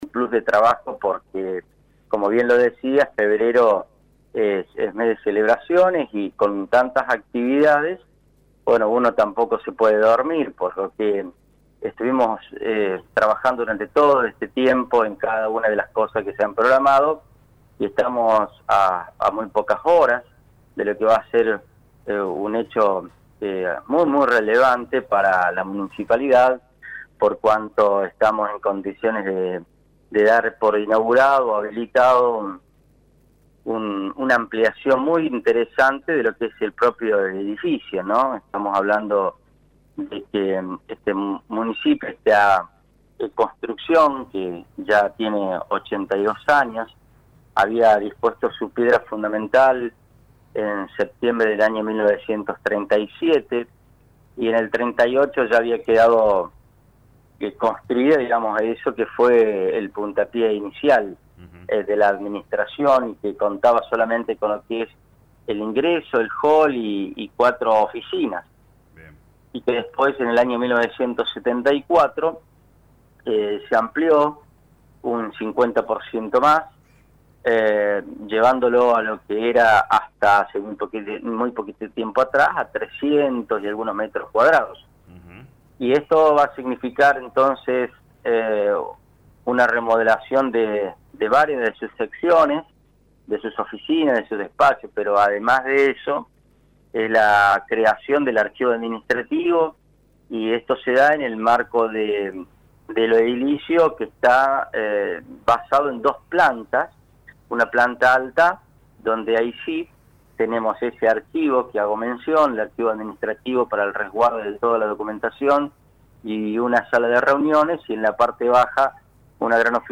El intendente Carlos Baigorria adelantó más obras en dialogo con LA RADIO 102.9.